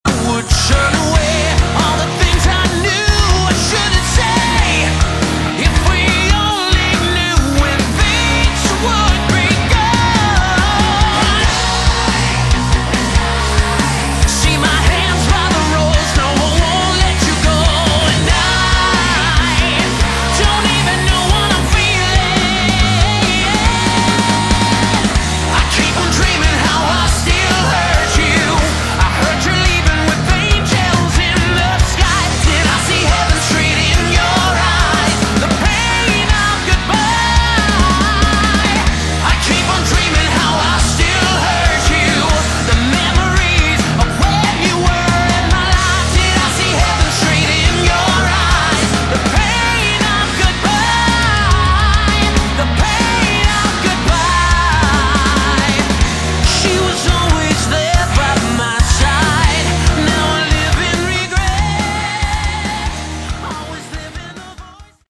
Category: Melodic Metal
vocals
guitar
bass
drums